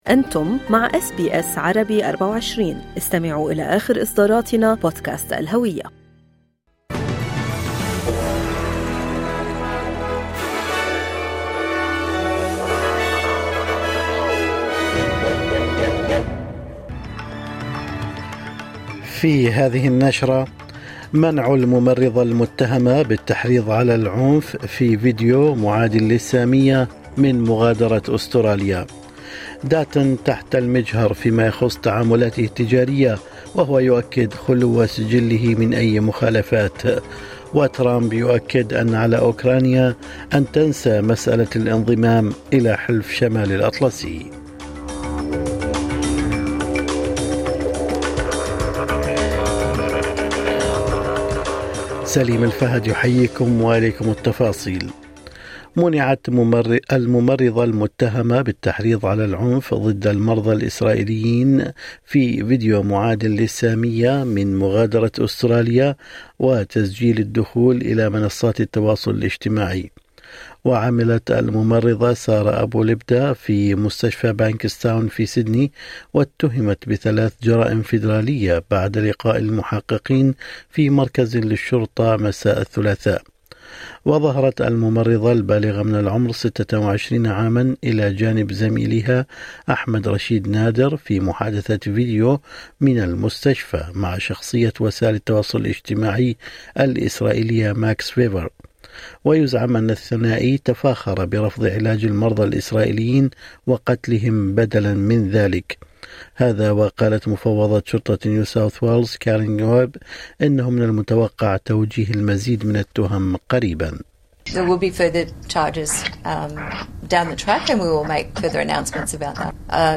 نشرة الأخبار